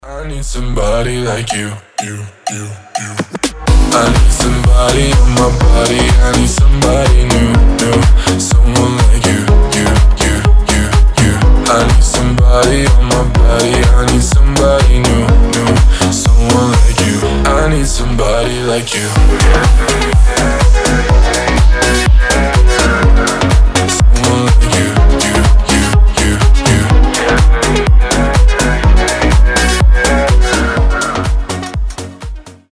• Качество: 320, Stereo
slap house